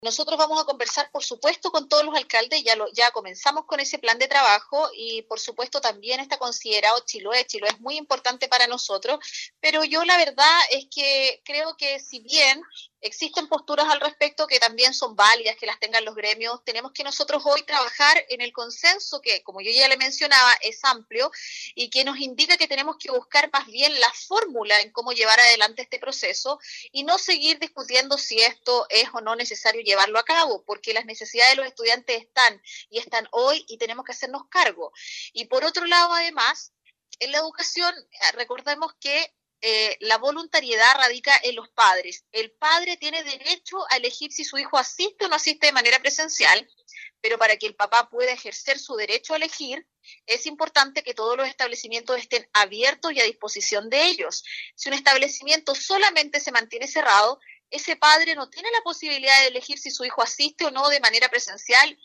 Así lo expresó la seremi de Educación de Los Lagos, Paulina Lobos, al proyectar el proceso educativo una vez que concluyan las vacaciones de invierno.
21-SEREMI-EDUCACION-PAULINA-LOBOS-2.mp3